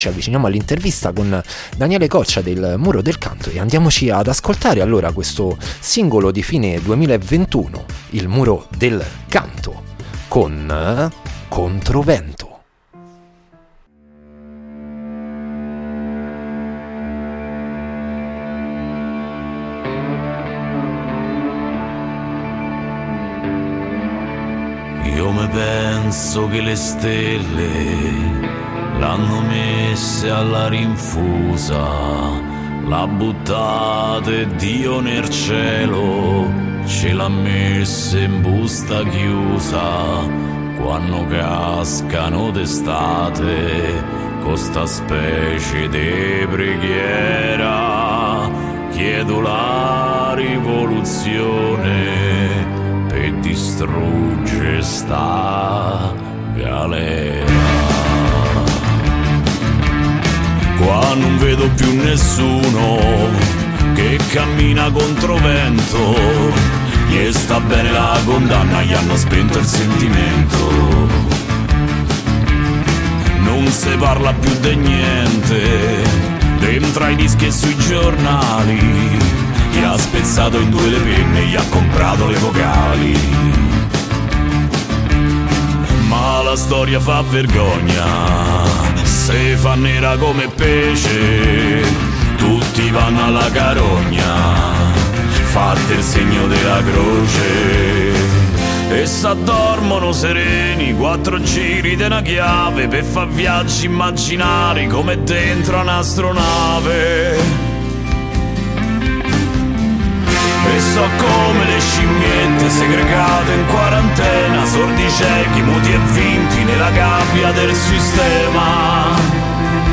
Artista a la Vista Interviste